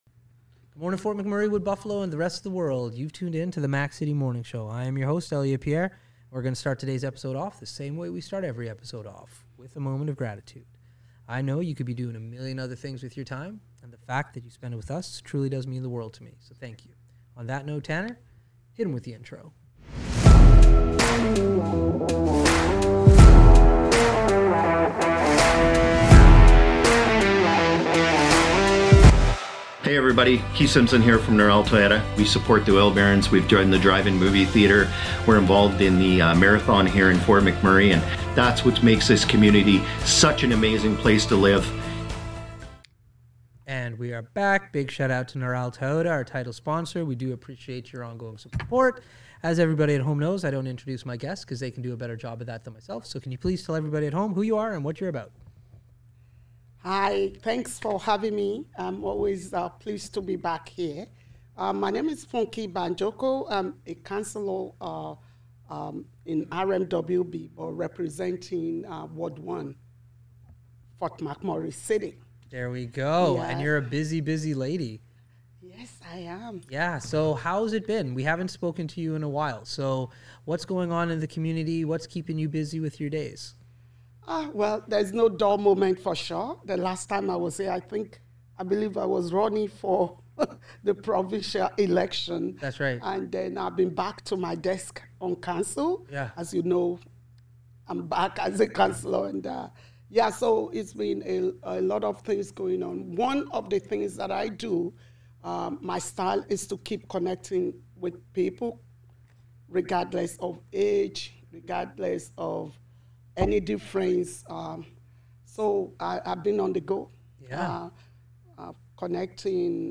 We are excited to have our friend Funke Banjoko, a local Councilor on the show today!